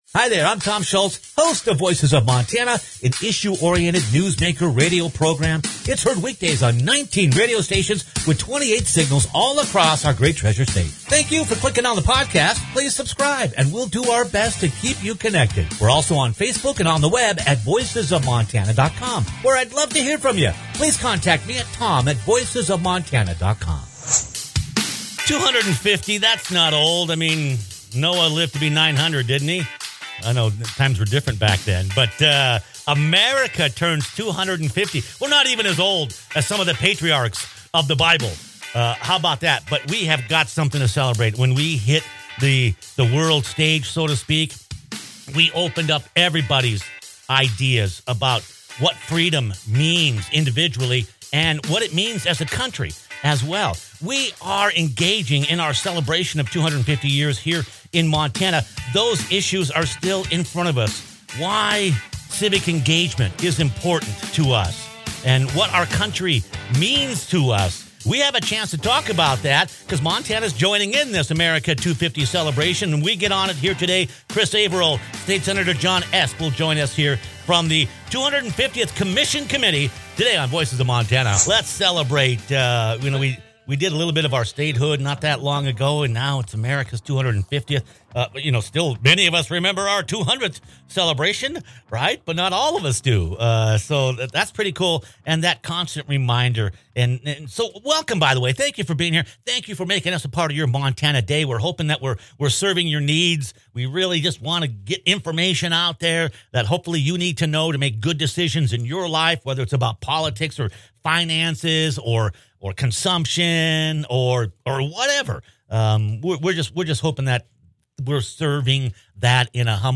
America turns 250 in 2026 — and Montana is already planning how to celebrate, educate, and engage. Click on the podcast for a discussion on the value of patriotism, civic engagement, and all the celebrations at hand.